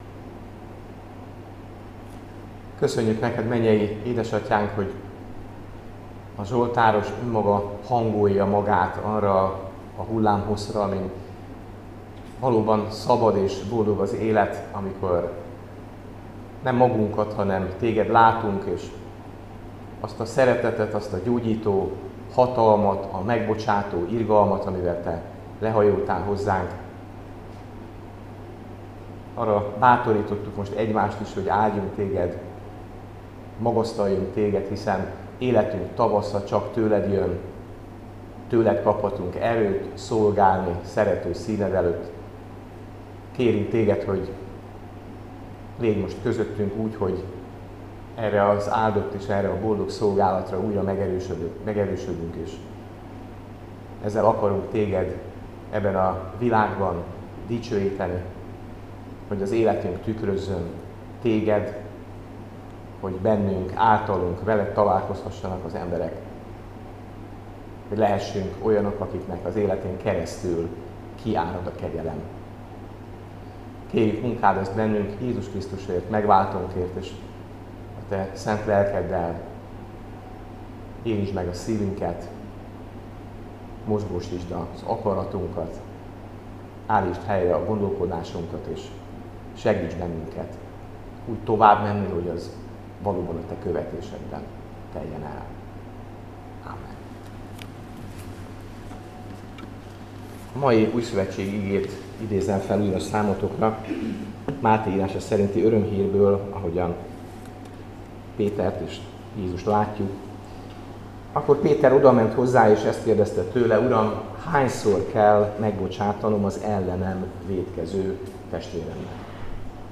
Áhítat, 2024. szeptember 3.